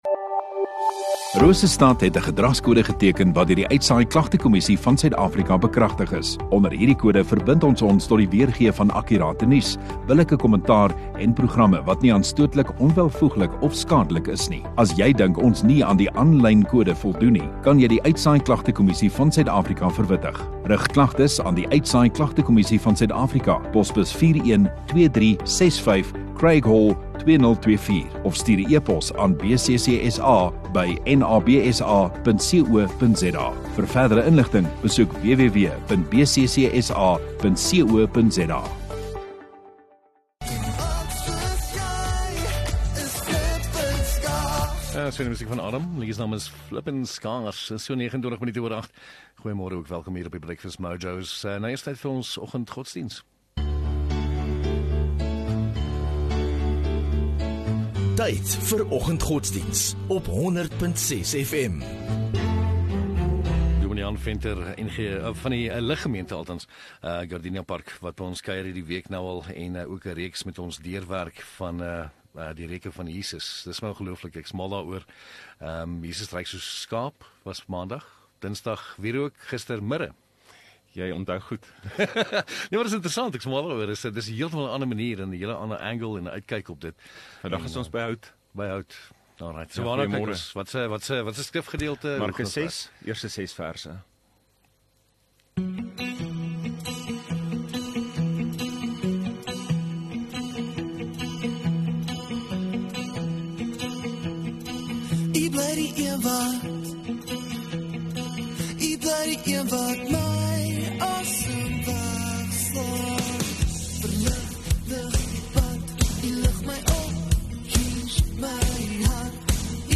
28 Mar Donderdag Oggenddiens